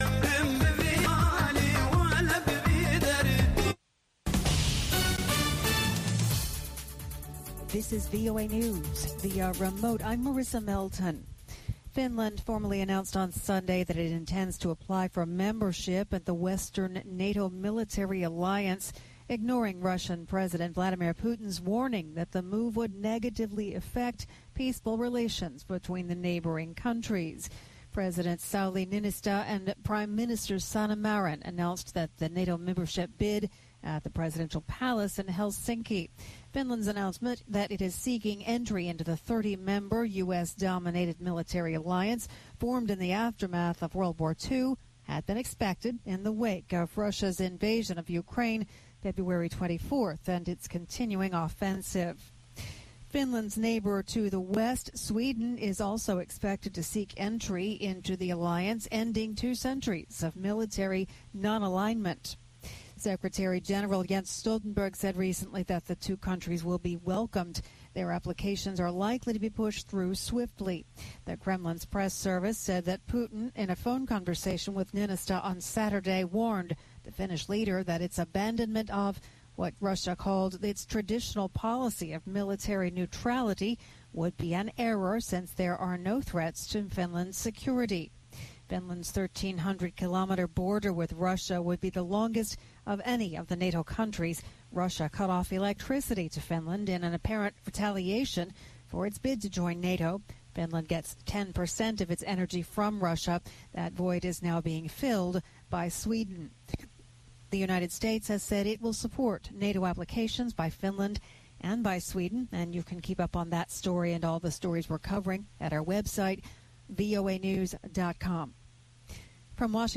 هەواڵەکانی 3 ی پاش نیوەڕۆ